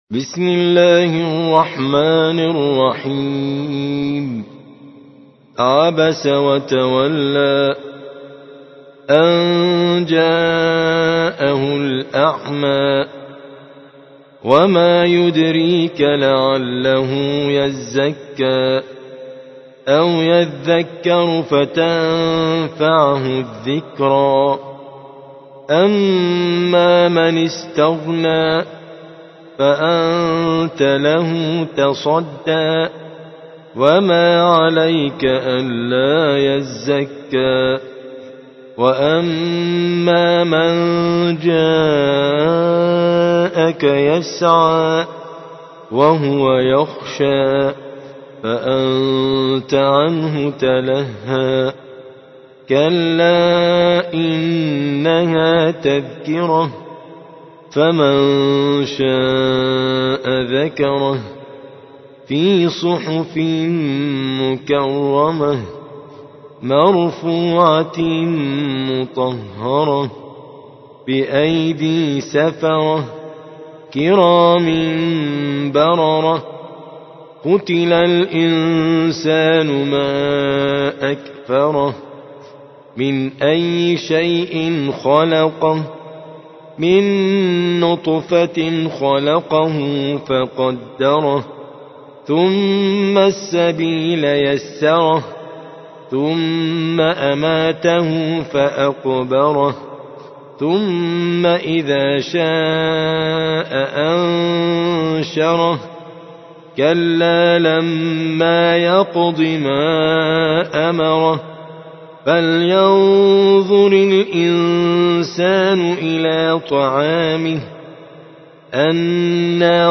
80. سورة عبس / القارئ